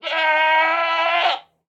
Minecraft Version Minecraft Version snapshot Latest Release | Latest Snapshot snapshot / assets / minecraft / sounds / mob / goat / scream2.ogg Compare With Compare With Latest Release | Latest Snapshot
scream2.ogg